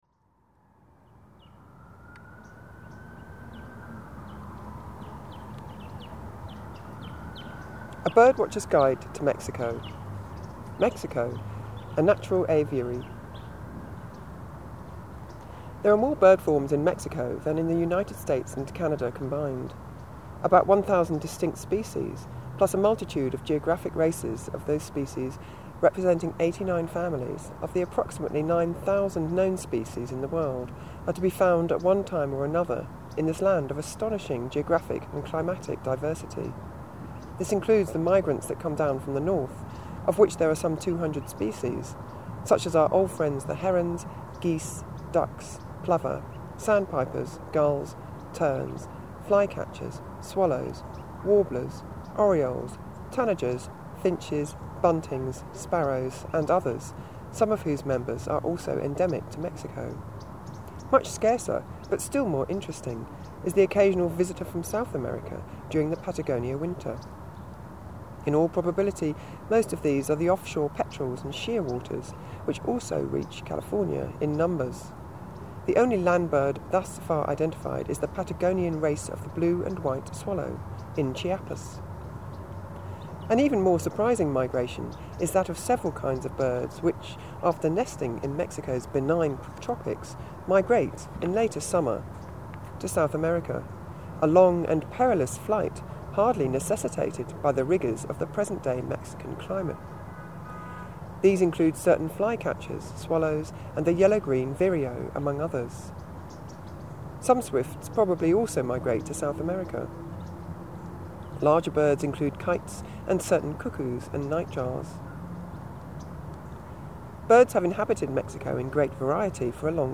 Uma componente da minha prática sonora é a leitura em voz alta em locais exteriores.
Os lugares que visito são geralmente urbanos e a minha voz e movimentos são audíveis, duas características que não são típicas das práticas de gravação de campo.
A leitura do texto em voz alta no local, faz com que a minha voz se torne parte do ambiente sonoro através das palavras lidas.
A crueza da gravação em bruto convida o ouvinte remoto a experimentar uma dinâmica distinta na qual o lugar se pode revelar.
Esta leitura ocorre nos Jardines del Pedregal de San Ángel, na Ciudad de México, um parque urbano acidentado dominado por uma grande colina, com vista para a cidade, a partir do sul.
Local: Jardines del Pedregal de San Ángel, Cidade do México